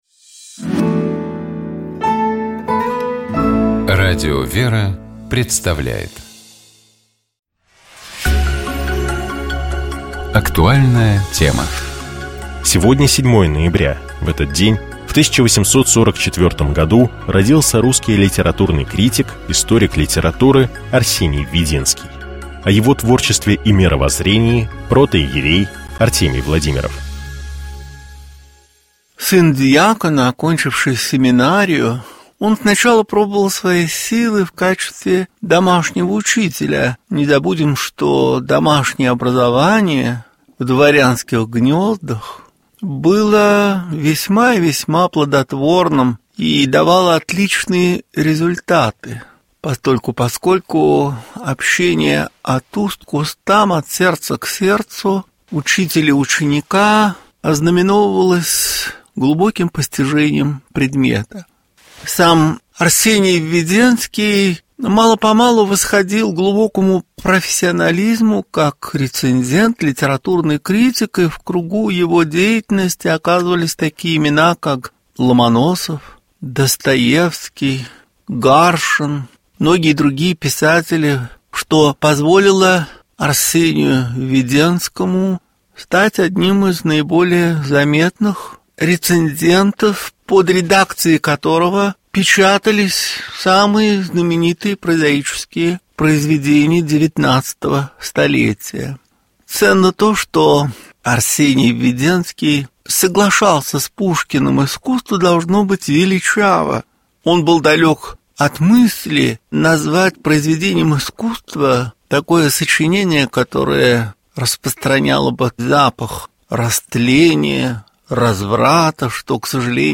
В праздник Входа Господня в Иерусалим Святейший Патриарх Московский и всея Руси Кирилл совершил Литургию в Храме Христа Спасителя. На проповеди по завершении богослужения Предстоятель Русской Православной Церкви говорил о подлинной радости христианина.